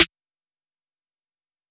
Murda SN.wav